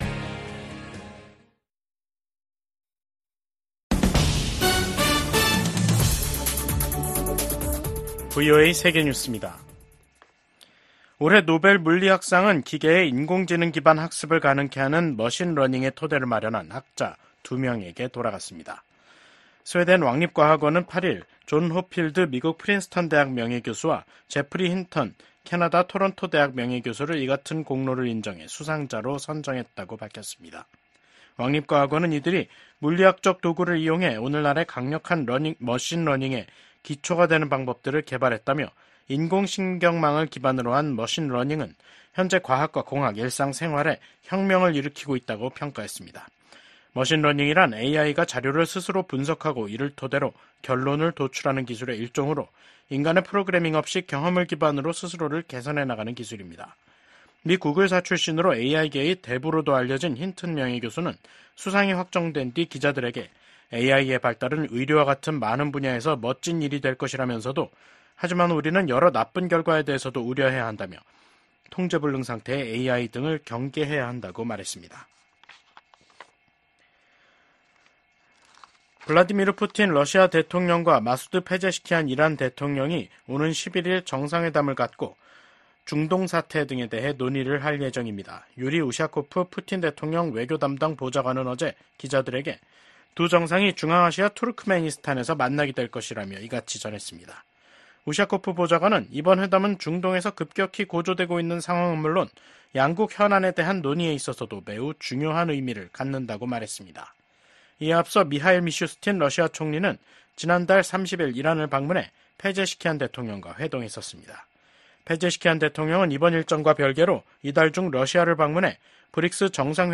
VOA 한국어 간판 뉴스 프로그램 '뉴스 투데이', 2024년 10월 8일 3부 방송입니다. 김정은 북한 국무위원장은 적들이 무력 사용을 기도하면 주저없이 핵무기를 사용할 것이라고 위협했습니다. 미국 정부가 북한 해킹조직 라자루스가 탈취한 가상 자산을 압류하기 위한 법적 조치에 돌입했습니다.